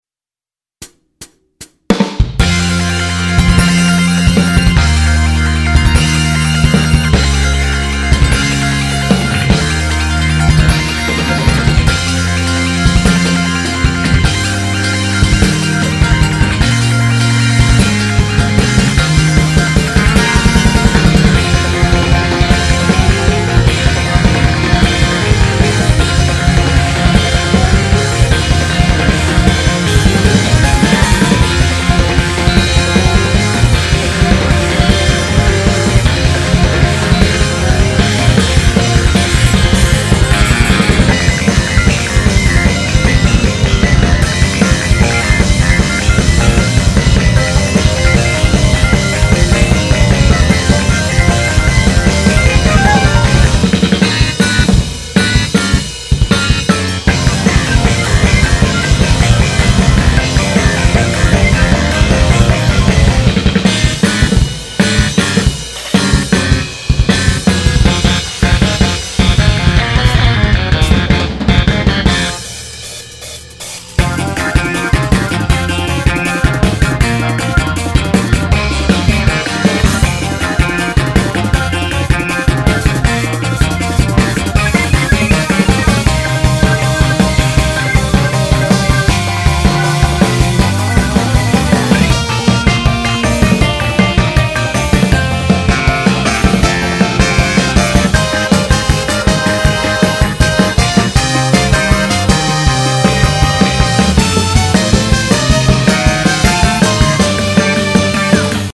■6/24の前奏直しver.
今回は逆にかなり高音が強く鳴ってるからもっと抑えてもいいかな。
低音（キックなのかベースなのか）を絞りすぎてもシャリ音質になっちゃうのがバランスわかんなくてまだ模索中。